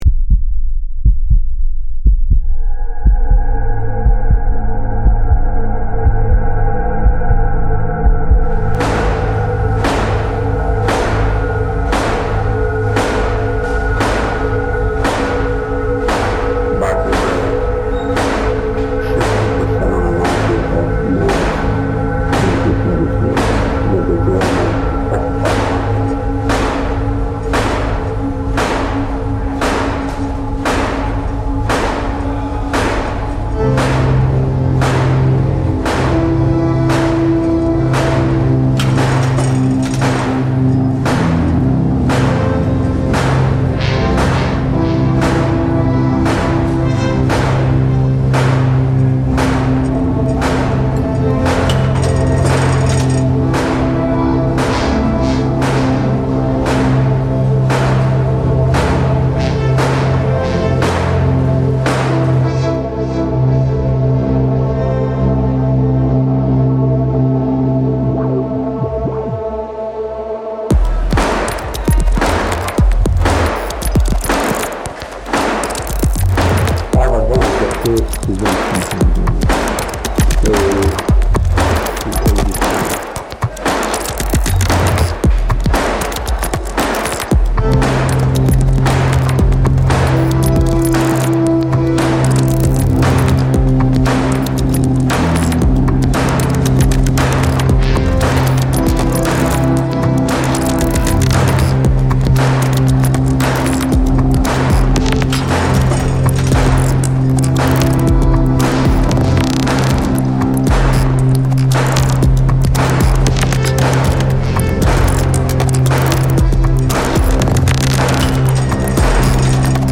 Marianosztra prison recording reimagined